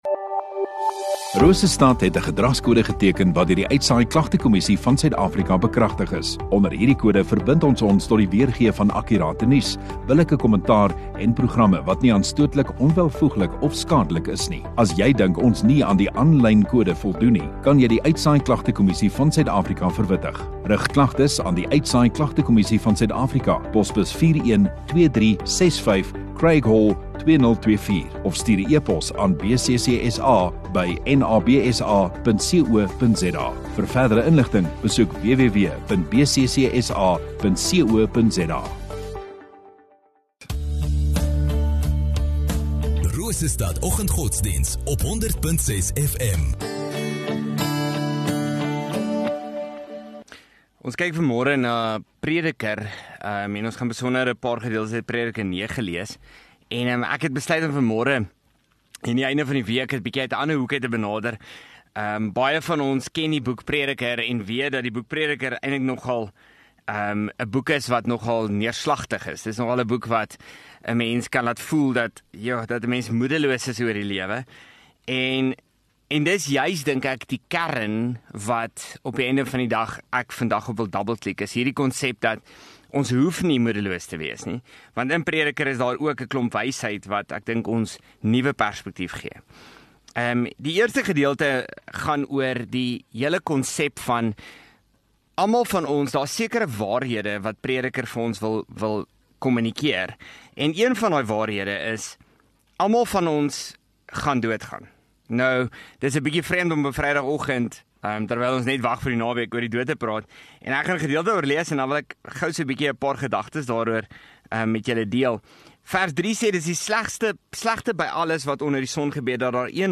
27 Feb Vrydag Oggenddiens